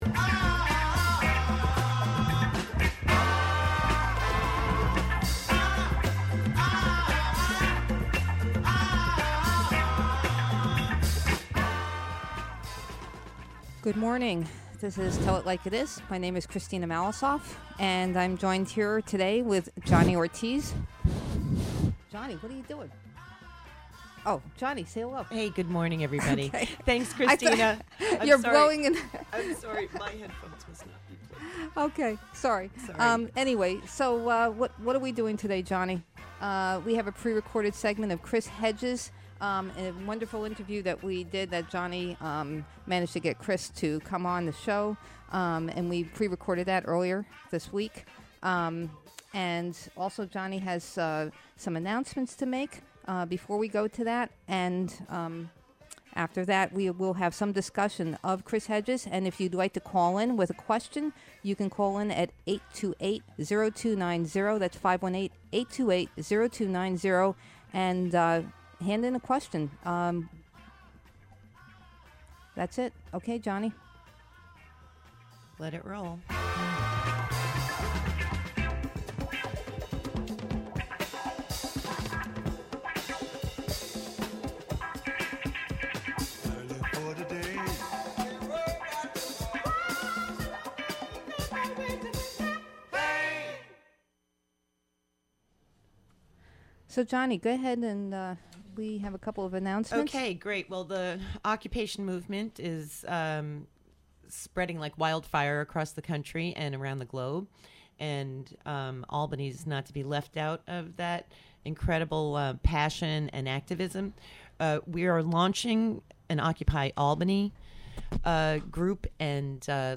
interview
performs "To The Light" live.